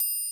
Triangle.wav